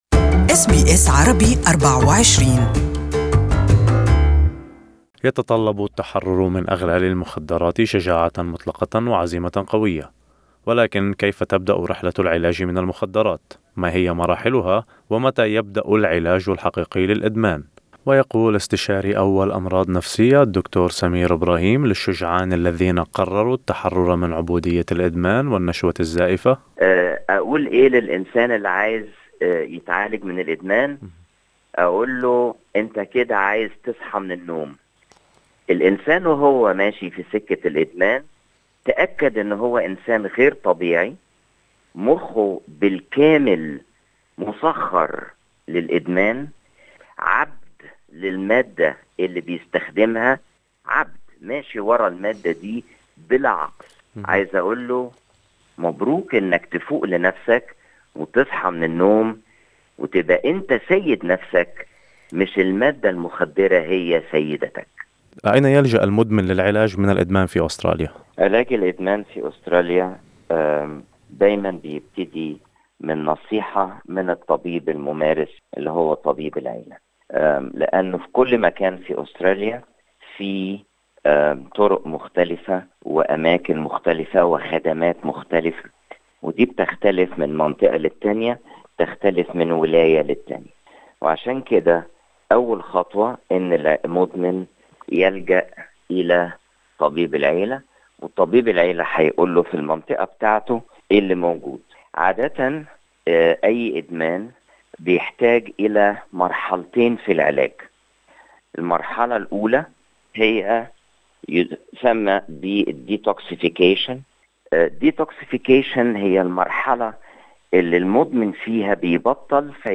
وهنا نص اللقاء المختصر.